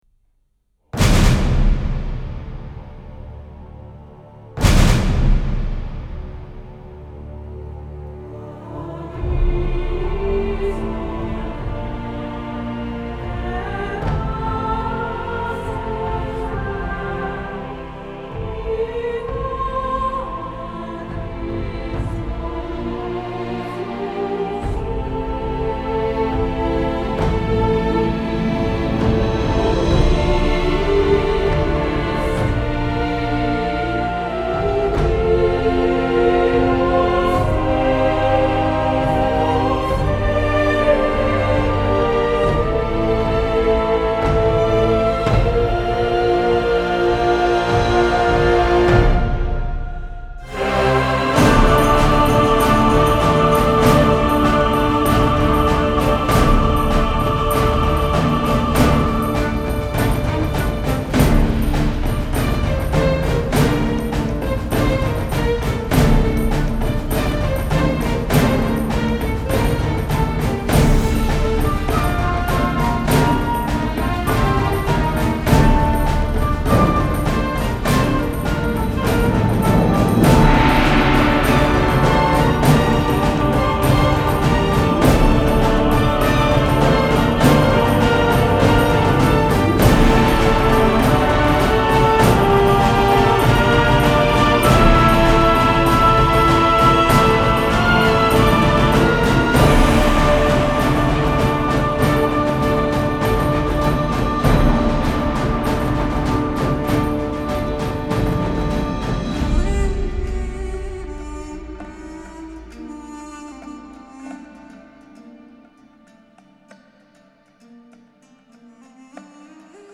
aufwendigen Orchestersoundtrack